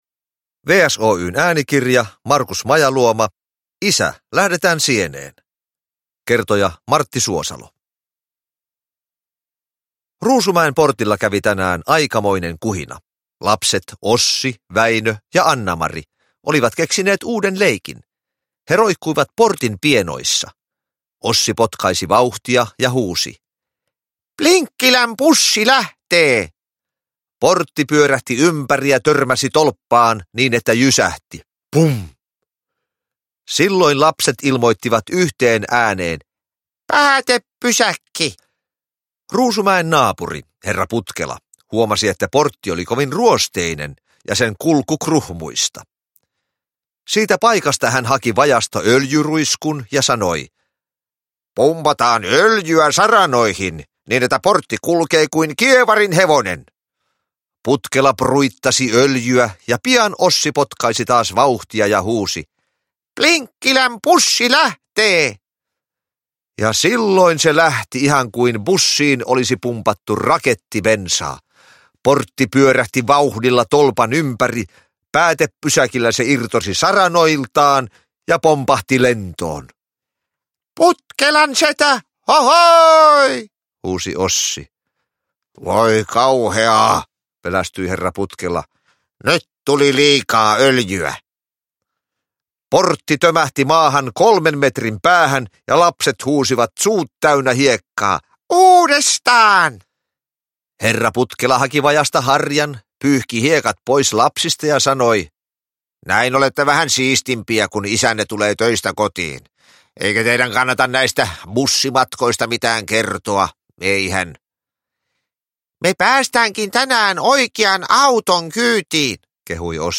Isä, lähdetään sieneen! – Ljudbok – Laddas ner
Näyttelijä Martti Suosalo on kertojana sarjasta tehdyissä äänikirjoissa.
Uppläsare: Martti Suosalo